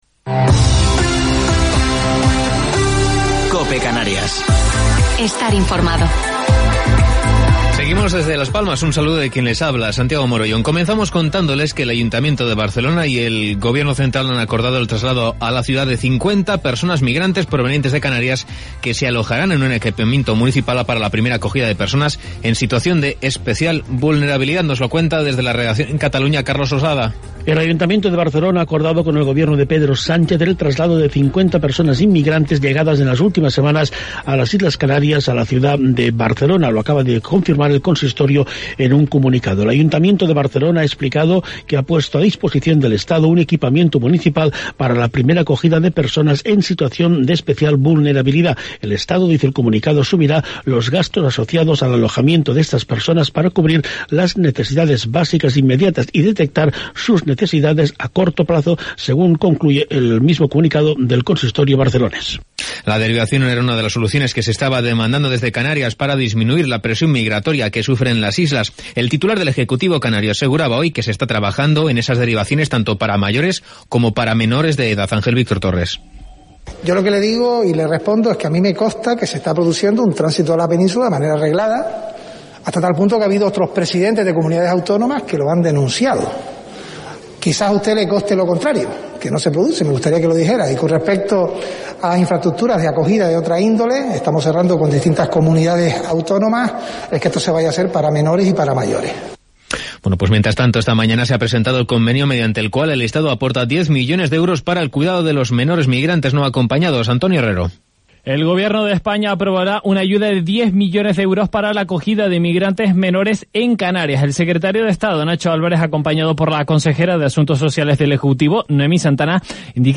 Gran Canaria Informativo local 1 de Diciembre del 2020